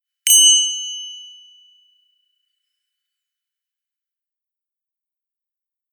熊よけ鈴の効果音
チリリーンと綺麗な音が鳴ります。
和風効果音82.『熊よけ鈴②』